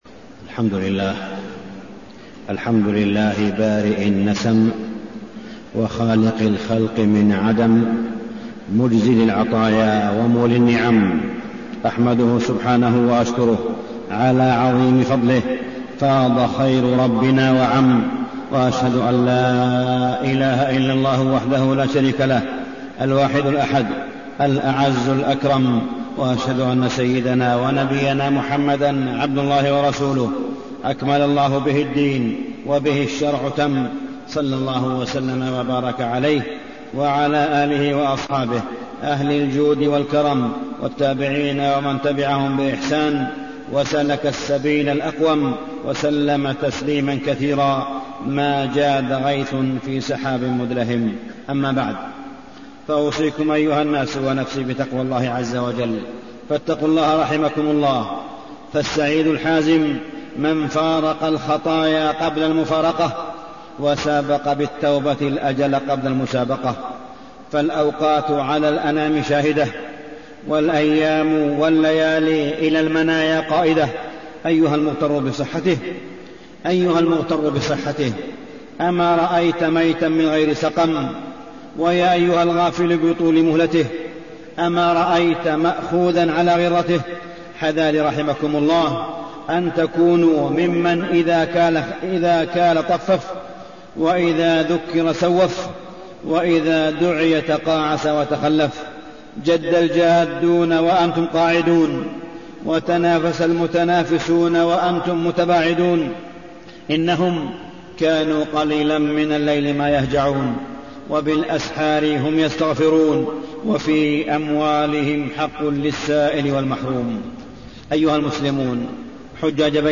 تاريخ النشر ١٧ ذو الحجة ١٤٣٠ هـ المكان: المسجد الحرام الشيخ: معالي الشيخ أ.د. صالح بن عبدالله بن حميد معالي الشيخ أ.د. صالح بن عبدالله بن حميد النصيحة فضلها ومكانتها وآدابها